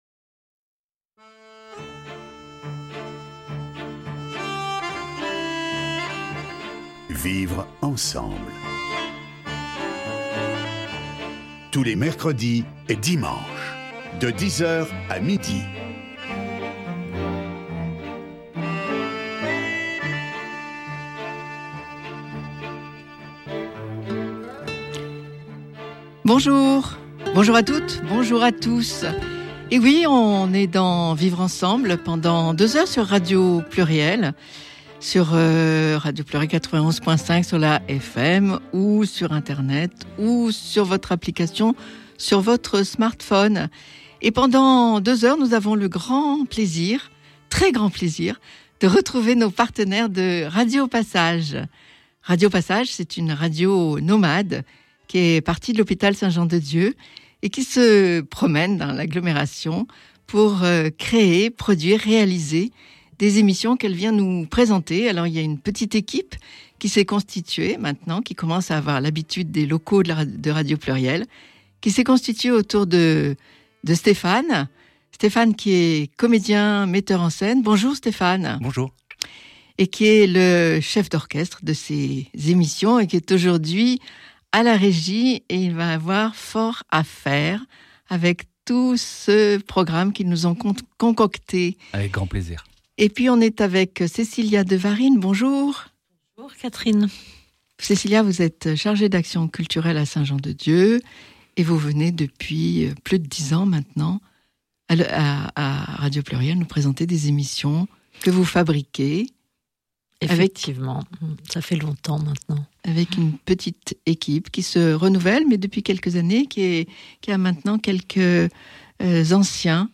Voici le premier acte de l’émission qui a été réalisée le 21 janvier 2026 dernier sur Radio Pluriel.
Nous avons causé entre nous et sommes partis rencontrer des gens qui nous en ont parlé : un camionneur, des personnes âgées, des amis, des musiciens.
Le Quatuor Emana interprète un extrait du 2e mouvement du quatuor opus 76 n°3 de Joseph Haydn, un extrait du quatuor n°6 de Chostakovitch et un extrait d’une miniature « le Châle rouge » de Komitas.